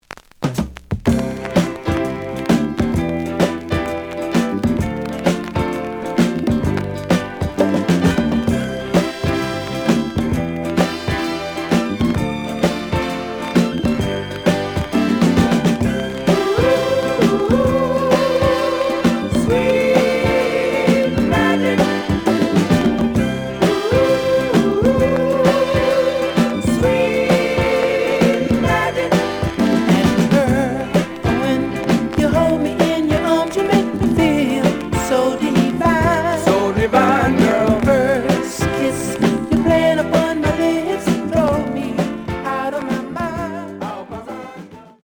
The audio sample is recorded from the actual item.
●Genre: Soul, 70's Soul
Some click noise on B side due to scratches.